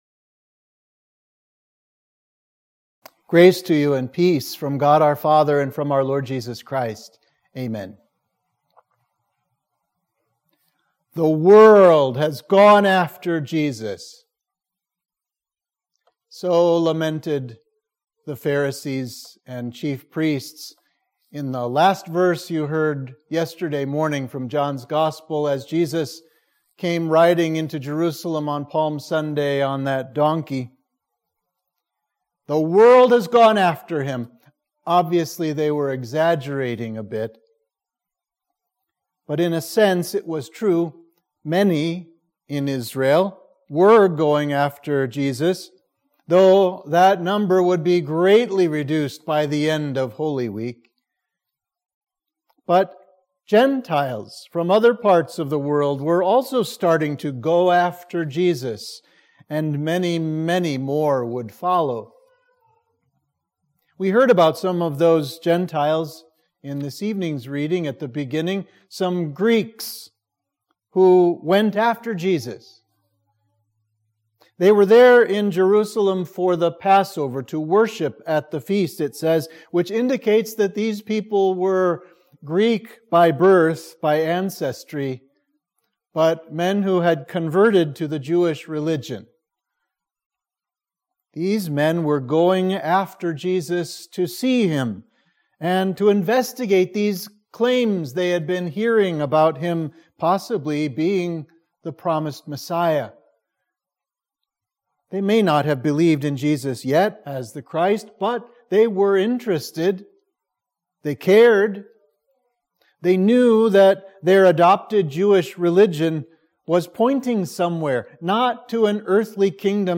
Sermon for Holy Monday